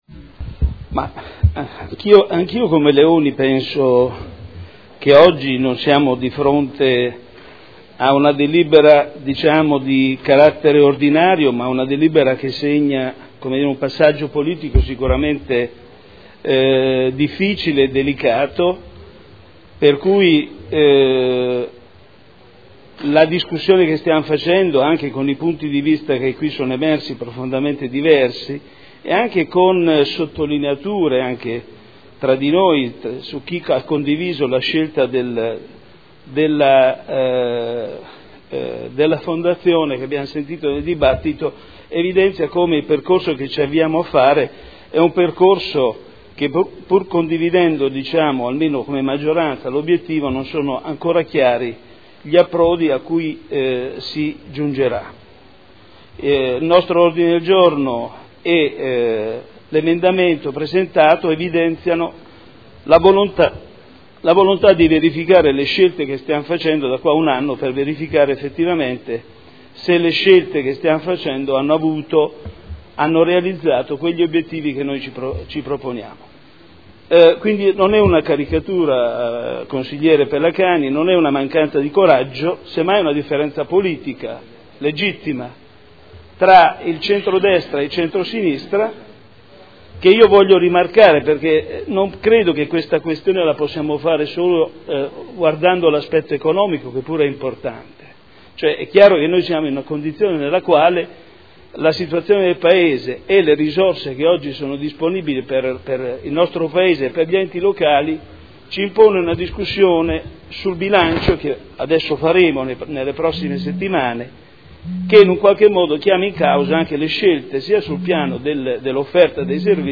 Seduta del 03/05/2012. Dibattito su proposta di deliberazione, emendamenti e Ordine del Giorno sulle scuole d'infanzia comunali